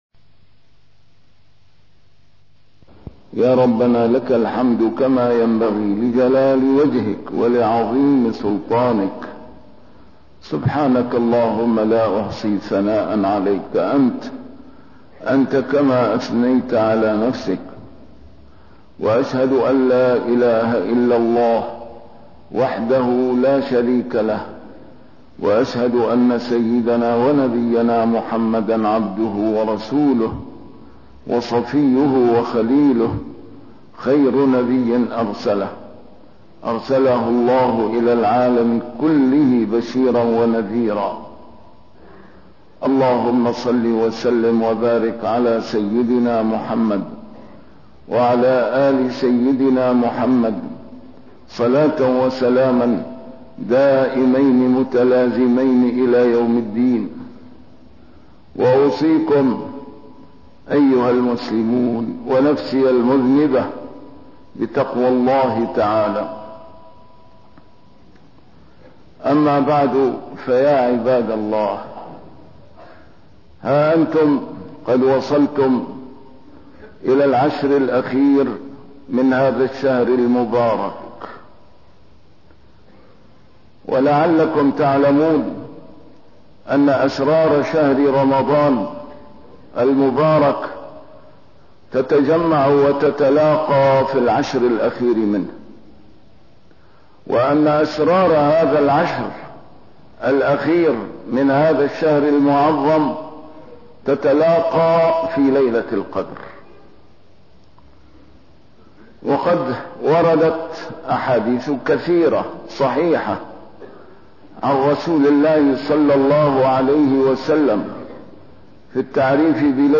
A MARTYR SCHOLAR: IMAM MUHAMMAD SAEED RAMADAN AL-BOUTI - الخطب - الثقافة الإسلامية هي المنجاة من الدجل الذي يمارس ضدنا